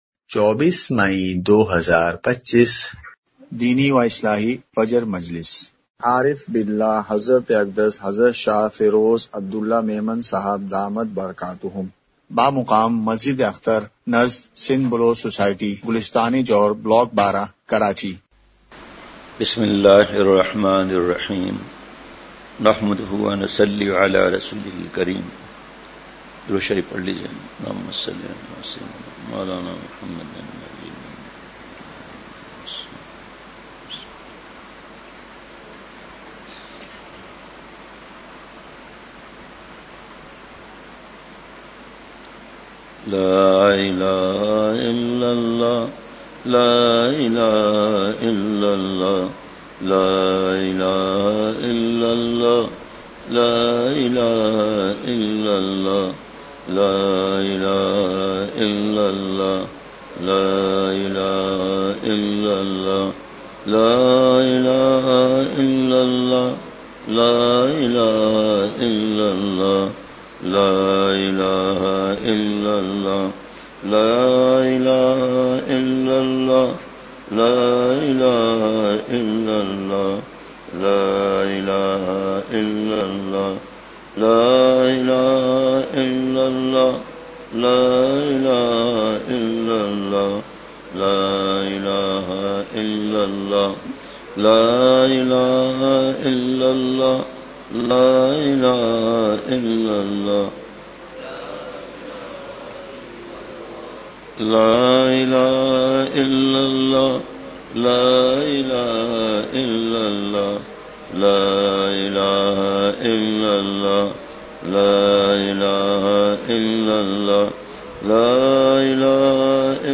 اصلاحی مجلس کی جھلکیاں مقام:مسجد اختر نزد سندھ بلوچ سوسائٹی گلستانِ جوہر کراچی